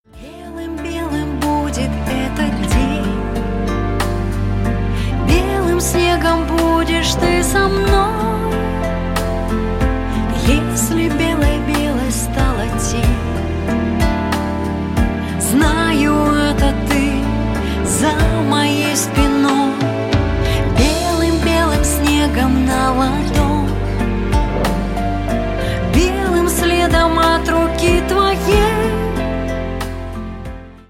красивые
женский вокал
мелодичные
спокойные
русский шансон